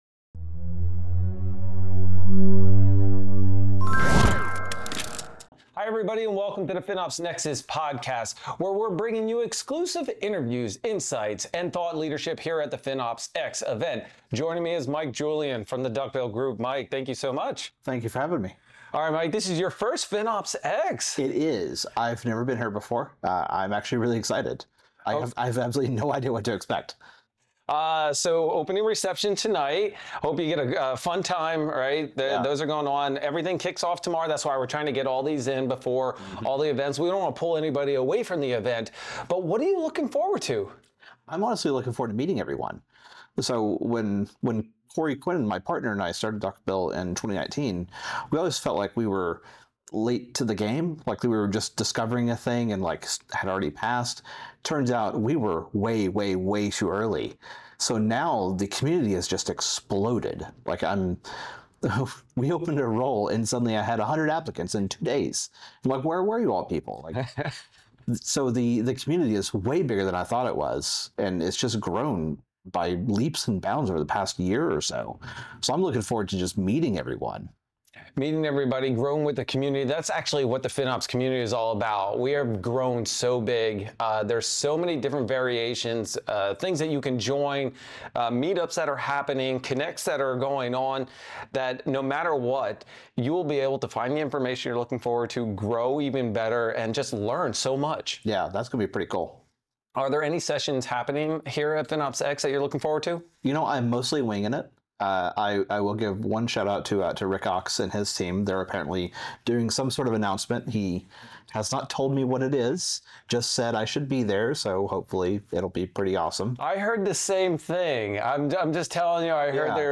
Join us for an exclusive interview
recorded live at the FinOps NEXUS event during FinOps X!